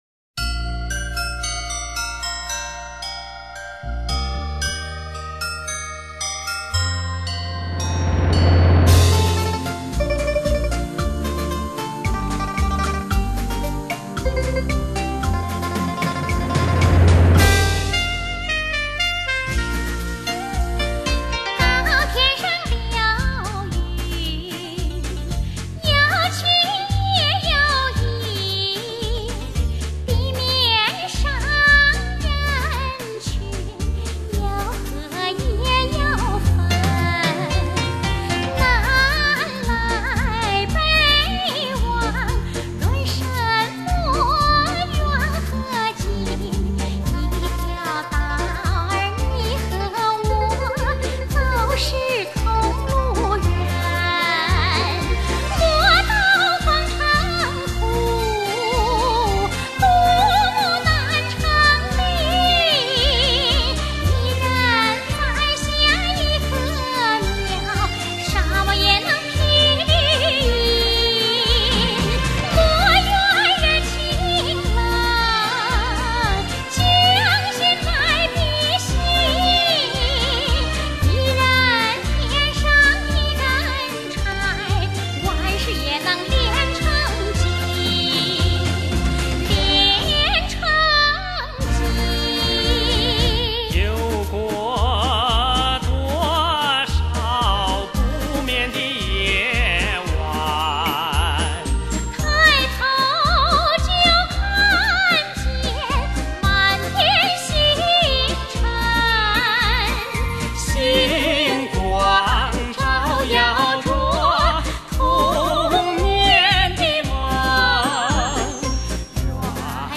萨克斯
小号
小提琴
二胡
笛子
曲调流畅而萧洒，节奏韵律高雅而别致，为舞迷营造出一个唯美传神的舞动意境。
伦巴